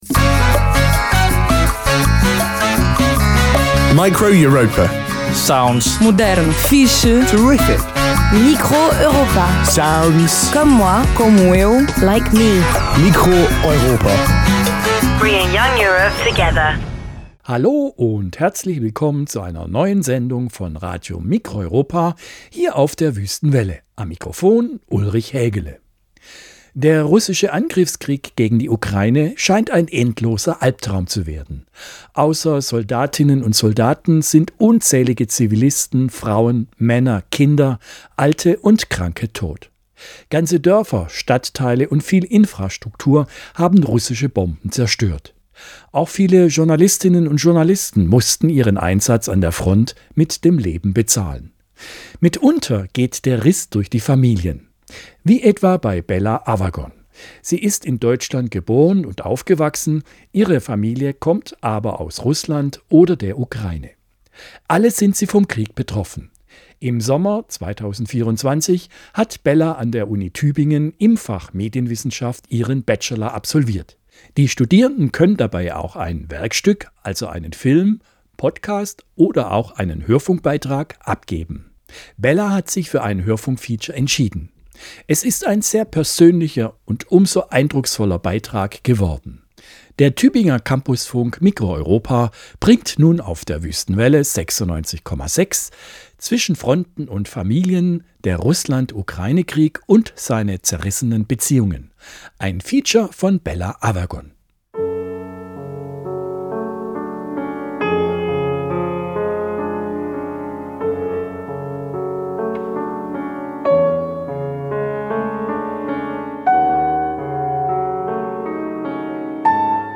Hörfunkfeature: Zwischen Fronten und Familien. Der Russland-Ukraine-Krieg und seine zerrissenen Beziehungen
Form: Live-Aufzeichnung, geschnitten